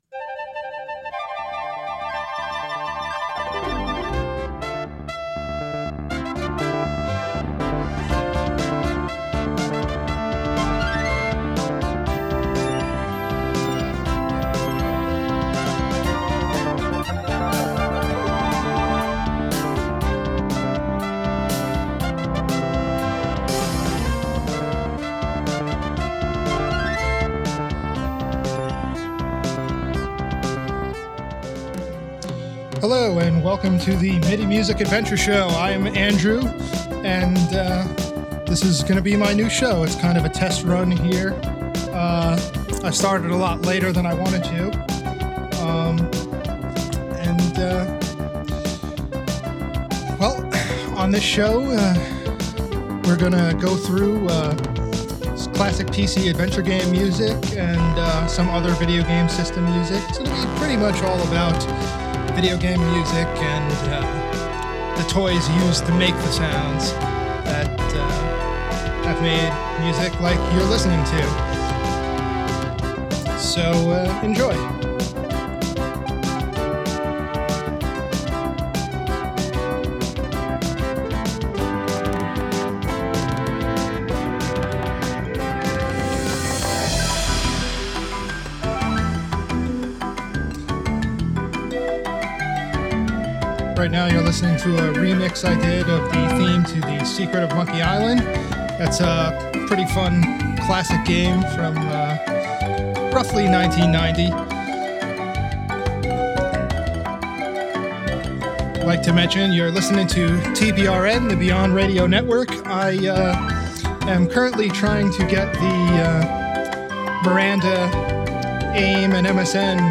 Why, The Last Crusade of the Magical Monkeys of coarse! It’s a nice Roland MT-32 showcase.